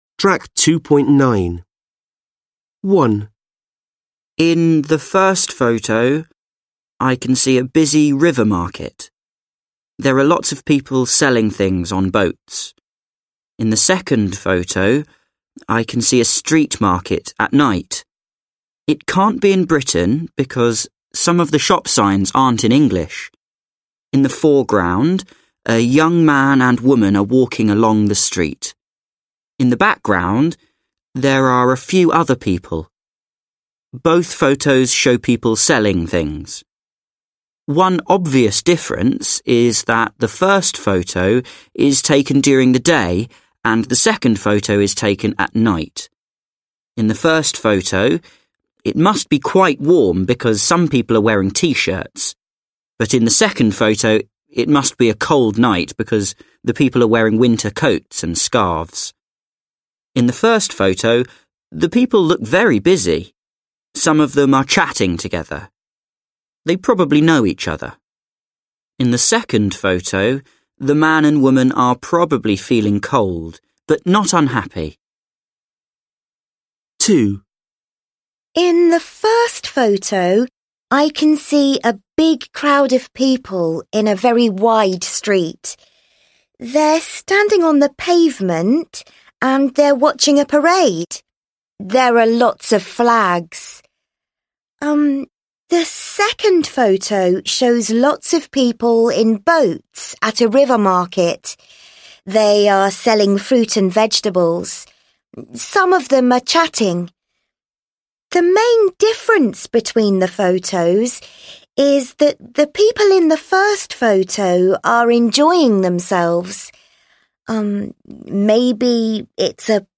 Then listen to two candidates comparing the photos.